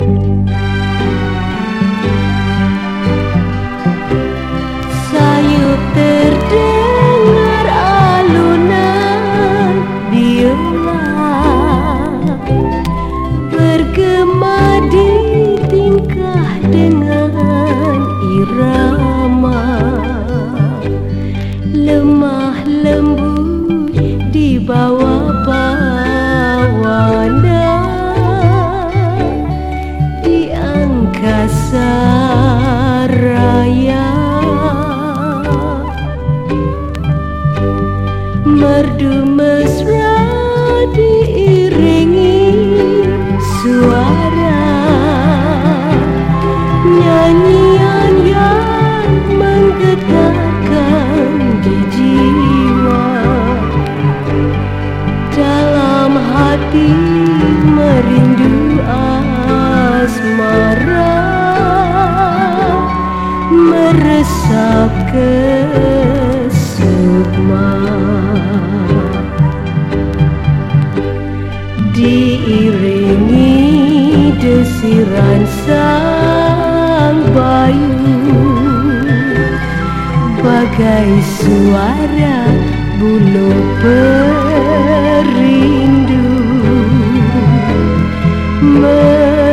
WORLD / BRAZIL / FORRO